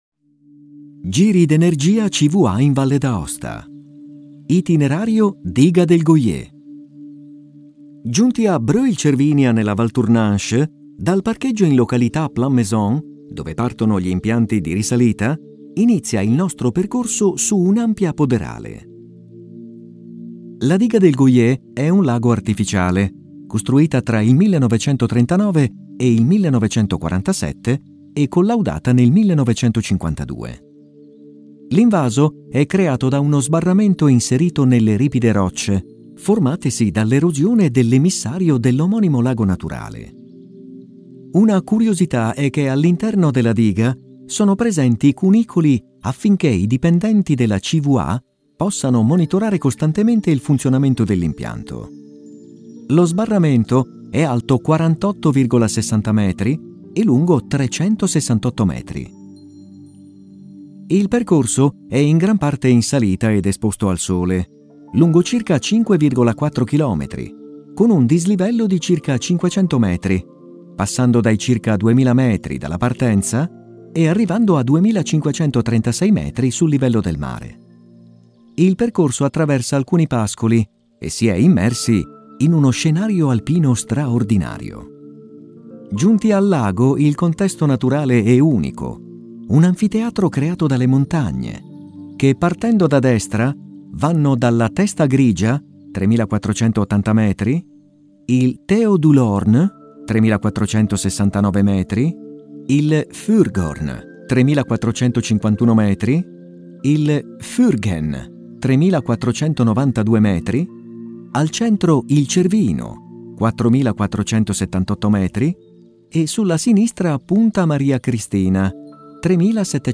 Audioguida Video LIS